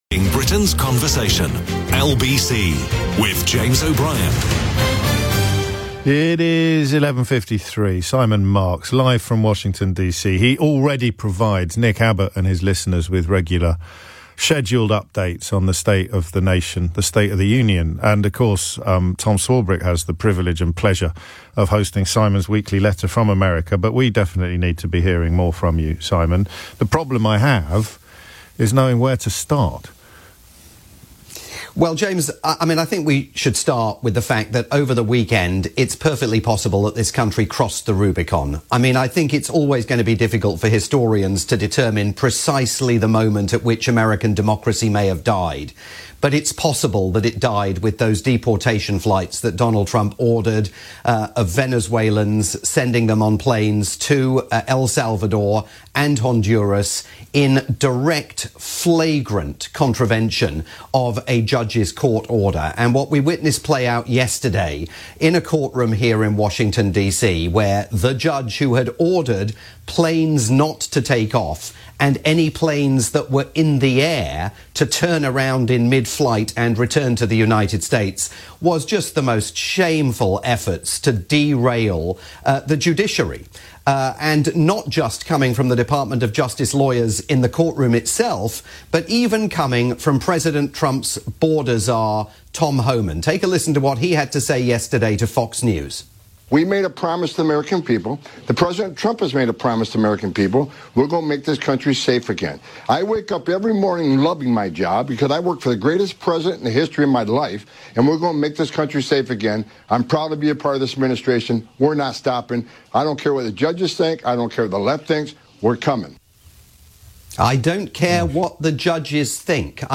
live update from Washington for James O'Brien's morning programme on the UK's LBC.